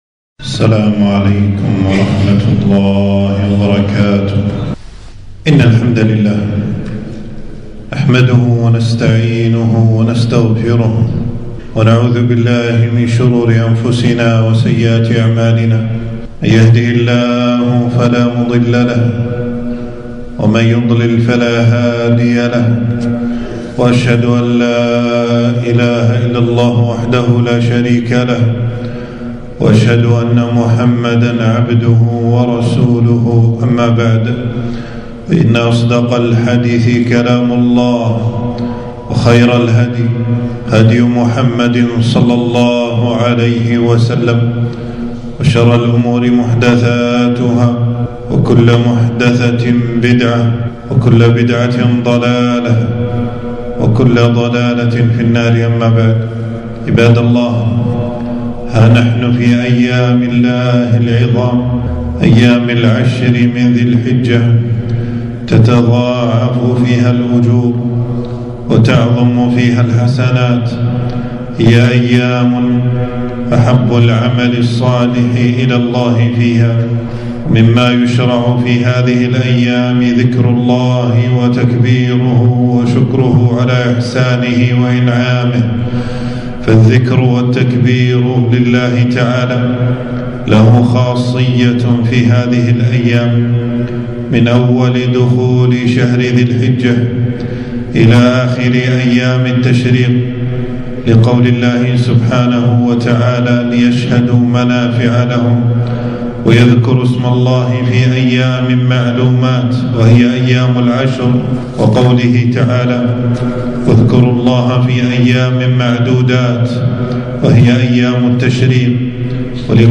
خطبة - فضل تكبير الله تعالى